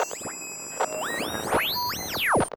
nerfs_psynoise6.ogg